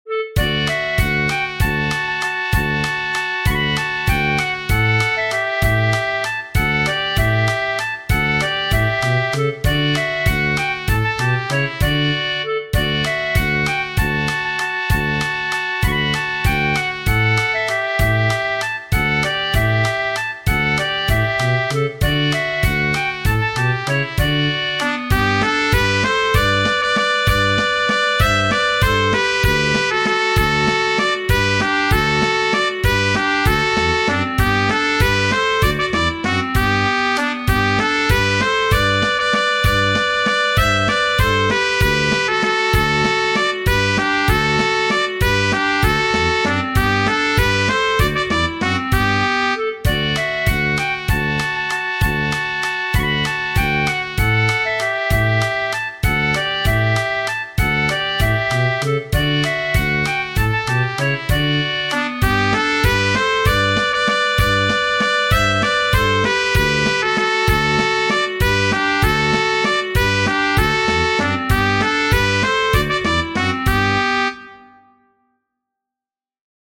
Tradizionale Genere: Folk Text by anonymous 1.
Folk tradizionale della Baviera (Germania)